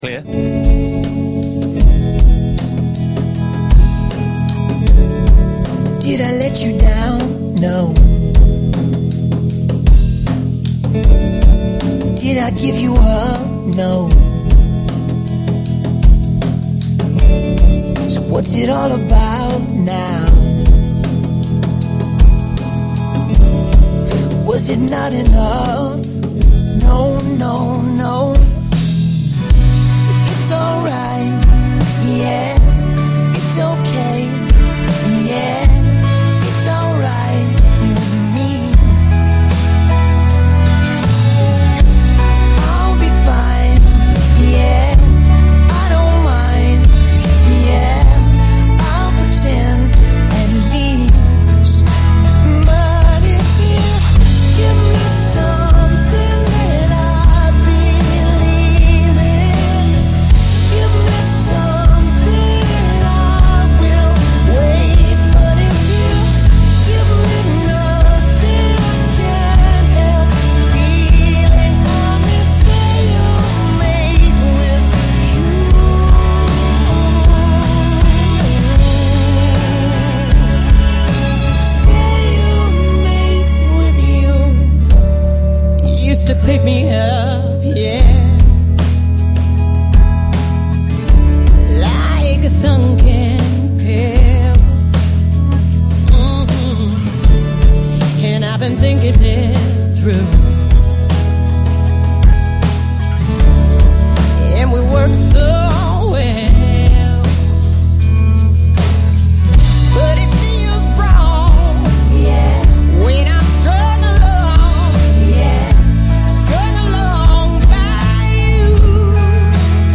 duetu
stáhnout radio rip